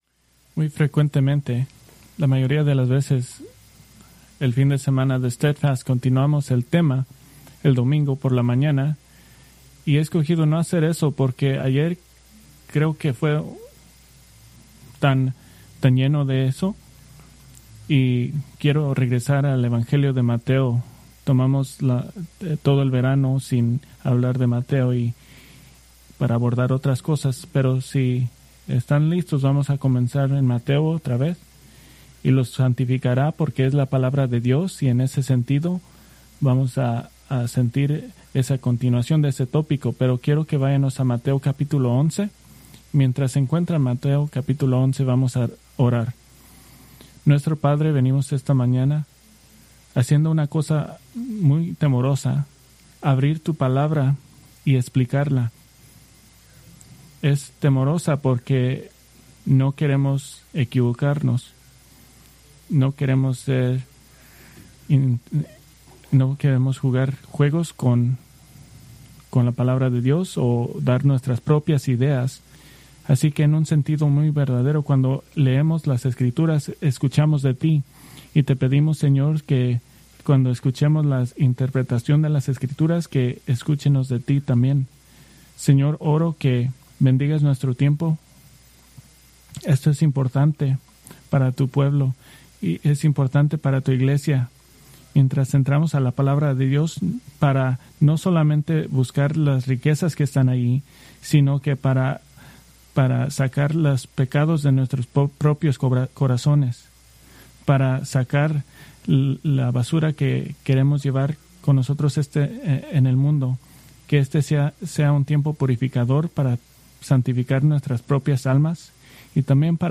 Preached September 28, 2025 from Mateo 11:1-6